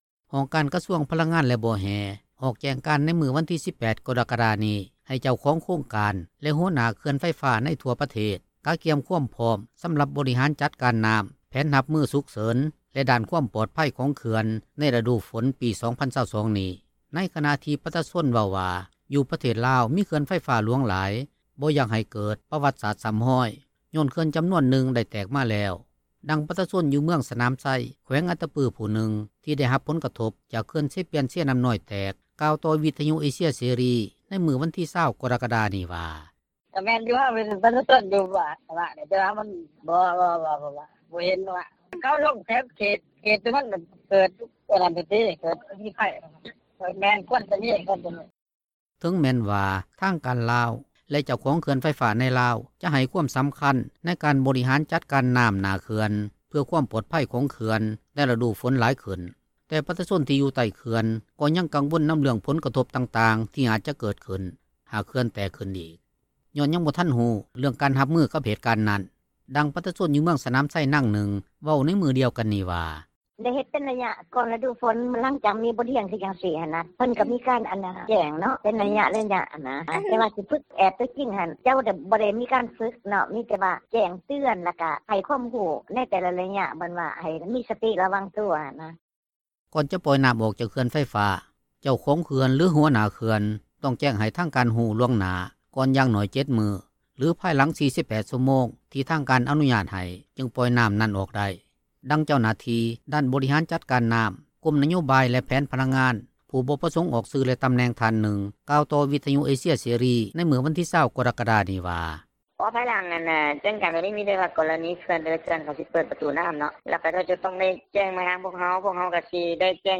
ດັ່ງປະຊາຊົນ ຢູ່ເມືອງສນາມໄຊ ແຂວງອັດຕະປື ຜູ້ນຶ່ງ ທີ່ໄດ້ຮັບຜົລກະທົບ ຈາກເຂື່ອນເຊປຽນ-ເຊນໍ້ານ້ອຍແຕກ ກ່າວຕໍ່ວິທຍຸເອເຊັຍເສຣີໃນມື້ວັນທີ 20 ກໍຣະກະດານີ້ວ່າ:
ດັ່ງປະຊາຊົນ ຢູ່ເມືອງສນາມໄຊນາງນຶ່ງ ເວົ້າໃນມື້ດຽວກັນນີ້ວ່າ: